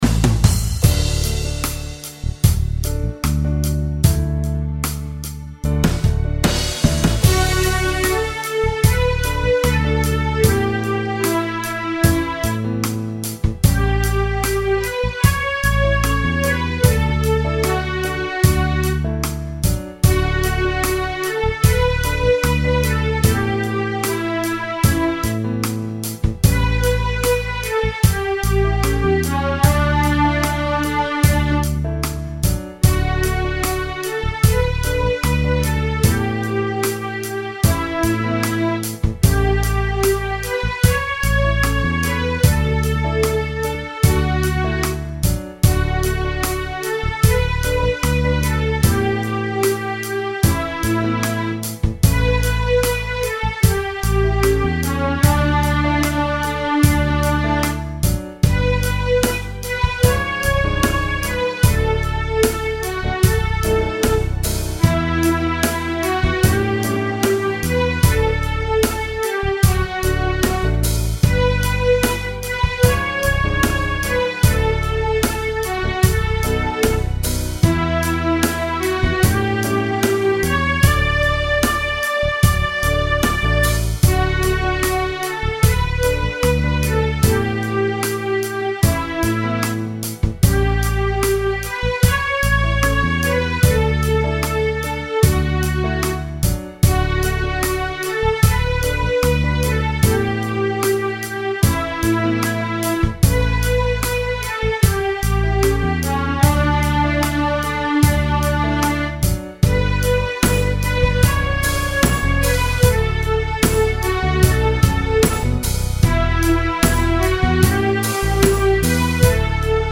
Slow Rock